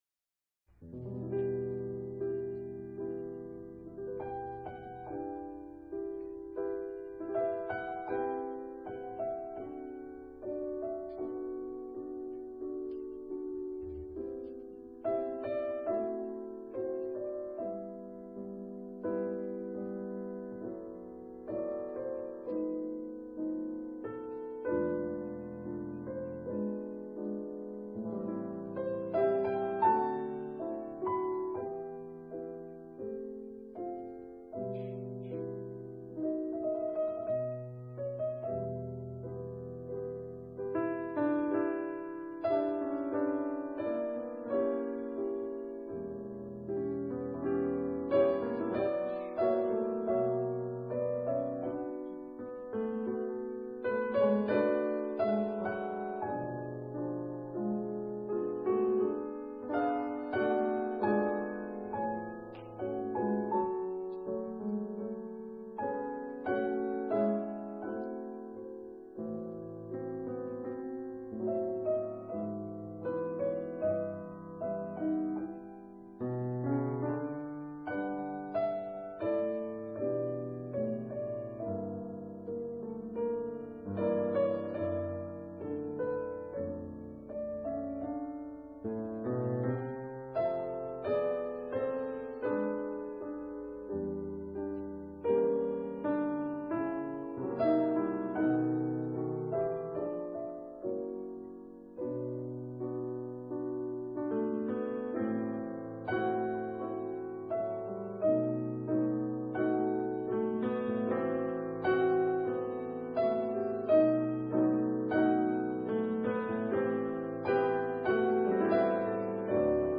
rec. live 1993